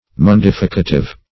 Search Result for " mundificative" : The Collaborative International Dictionary of English v.0.48: Mundificative \Mun*dif"i*ca*tive\, a. Cleansing.
mundificative.mp3